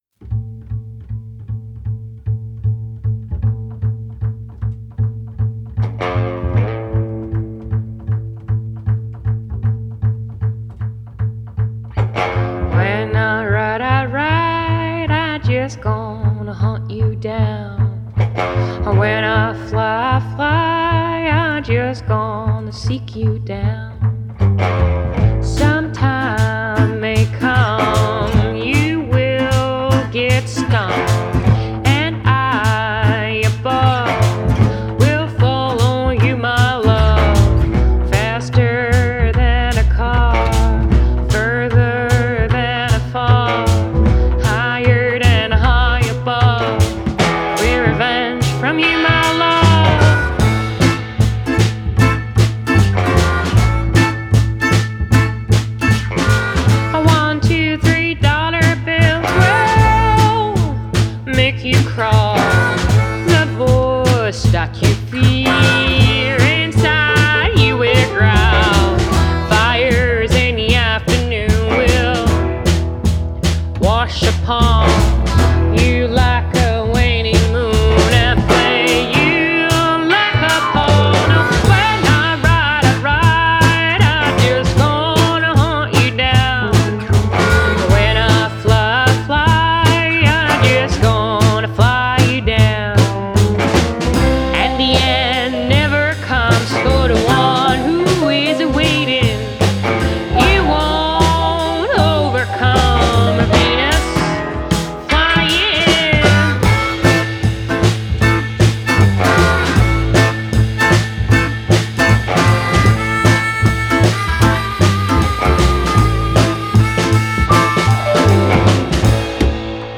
Genre: Folk, Indie, Country Folk, Female Vocal